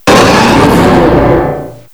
cry_not_mega_manectric.aif